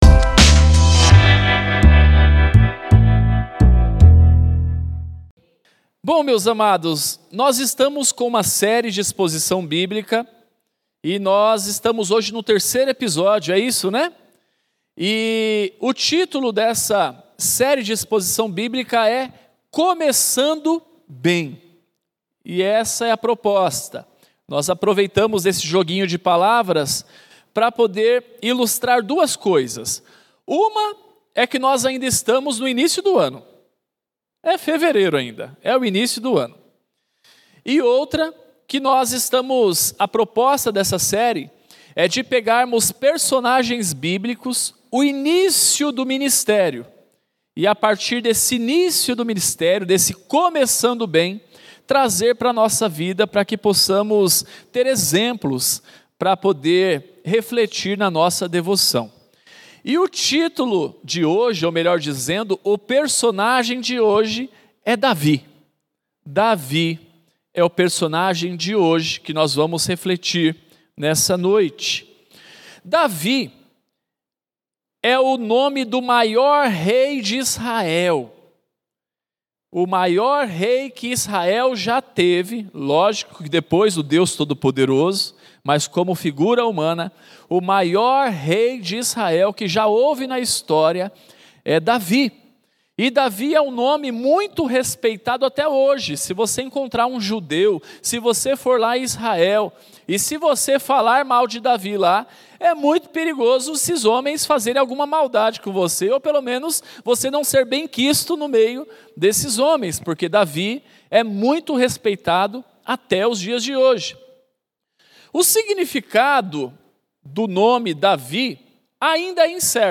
Mensagem realizado nos encontros de Reflexão de Oração às Quintas-Feiras 20h.